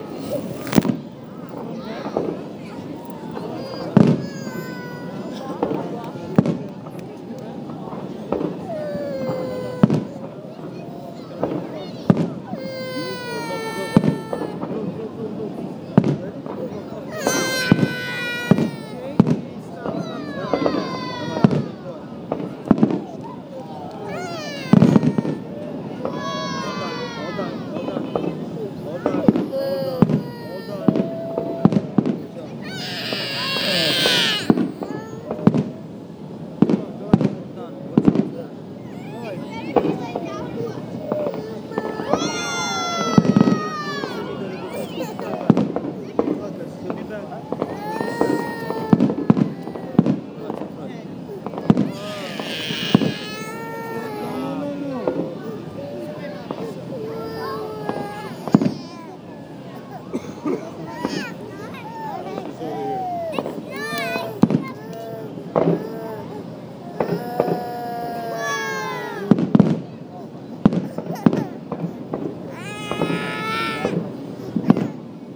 Here’s another minute of fireworks
Fireworks5.aiff